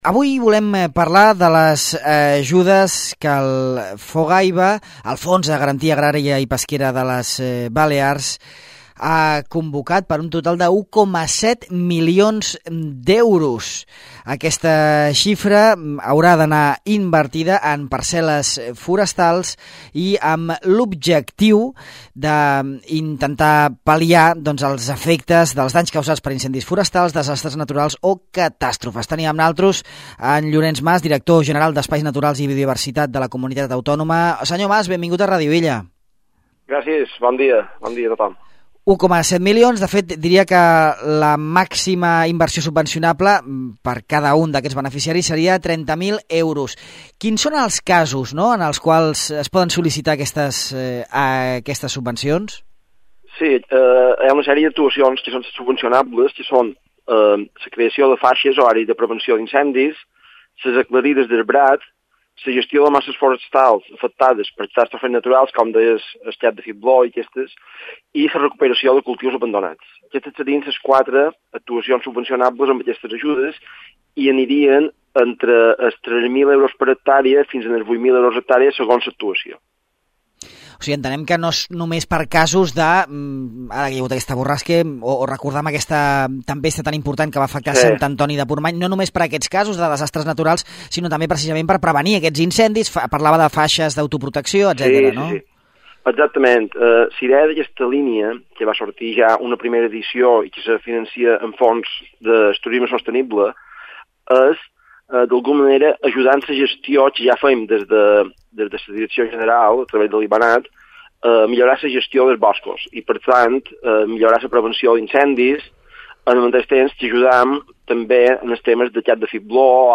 El Fons de Garantia Agrària i Pesquera de Balears (Fogaiba) ha convocat una subvenció, per un total d’1,7 milions d’euros, per realitzar inversions per prevenir els danys causats en els boscos per incendis forestals, desastres naturals i catàstrofes. Al de Far a Far en parlem amb Llorenç Mas, Director General d’Espais Naturals i Biodiversitat.